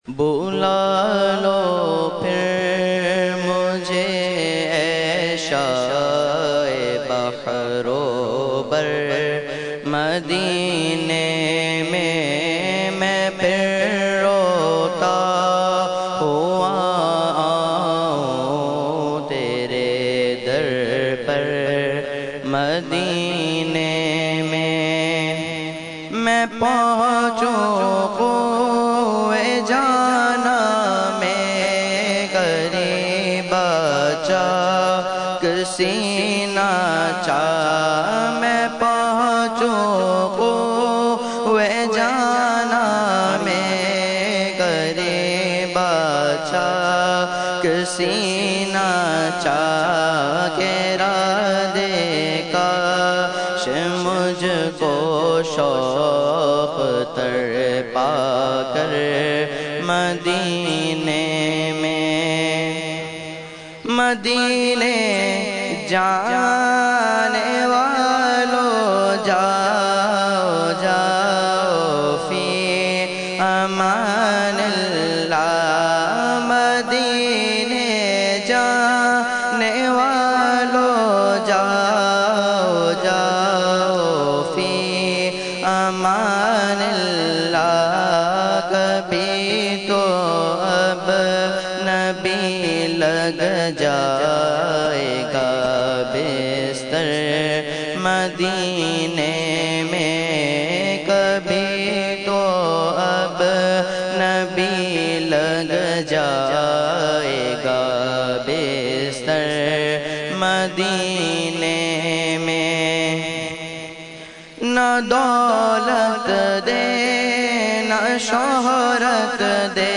Category : Naat | Language : UrduEvent : Urs Makhdoome Samnani 2014